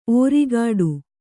♪ ōrigāḍu